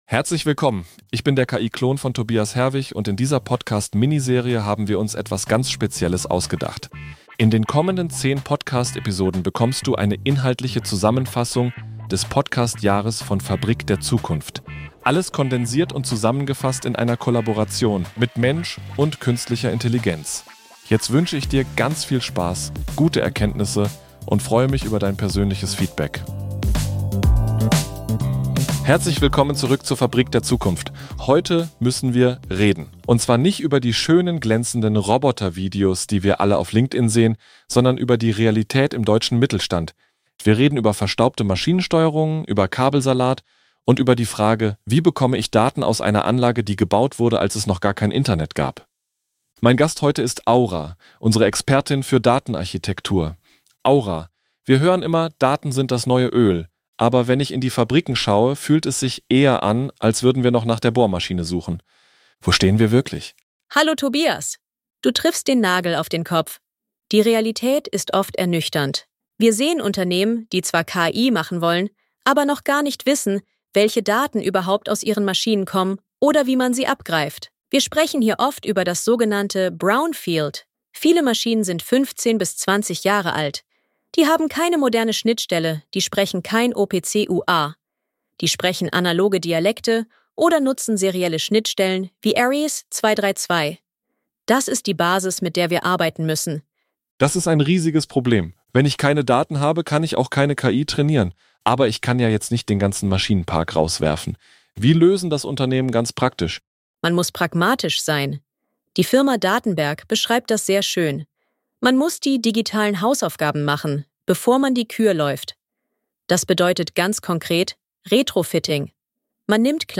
Gemeinsam mit Aura, einer generativen KI und